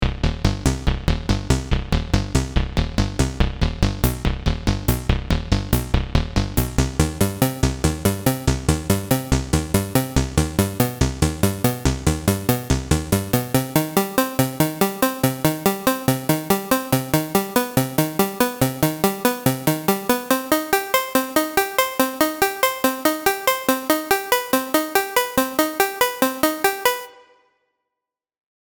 Below is an image of white and a sound example of a blended wave with a touch of noise added for extra bite.
BlendedWave-wNoise_-3dB.mp3